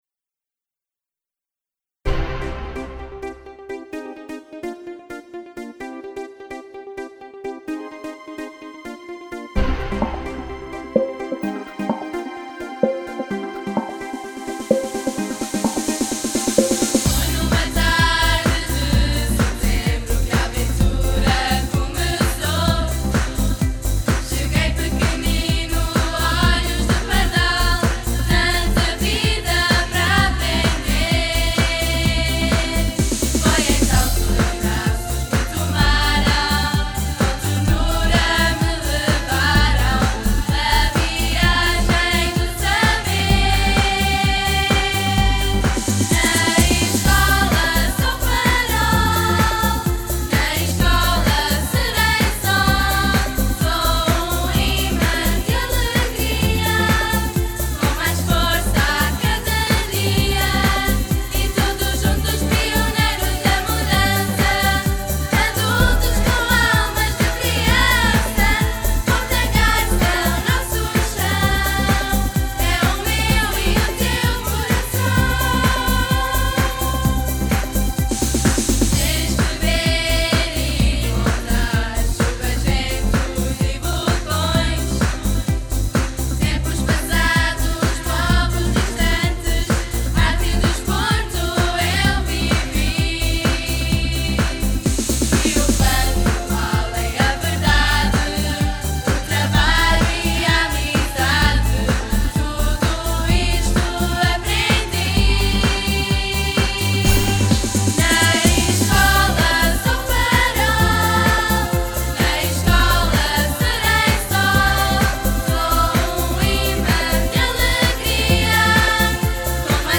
Mil-e-um-Farois-audio-c-voz.mp3